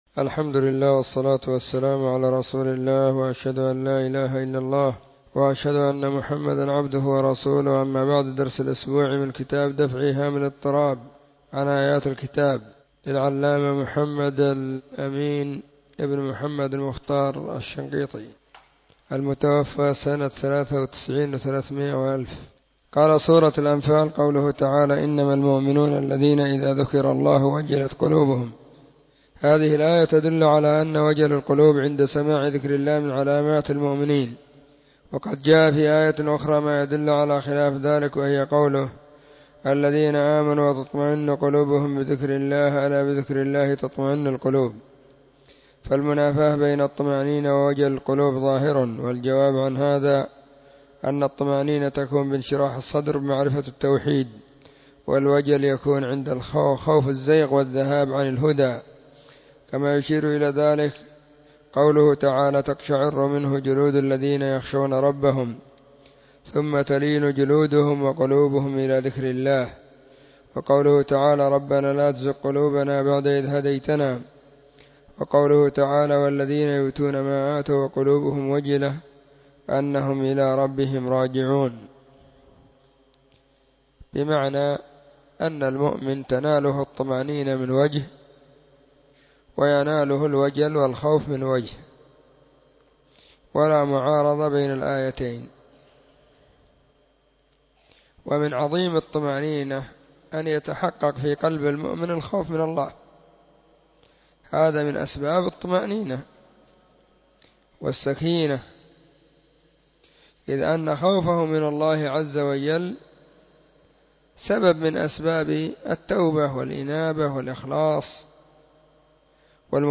⏱ [بعد صلاة الظهر في كل يوم الخميس]
📢 مسجد الصحابة – بالغيضة – المهرة، اليمن حرسها الله.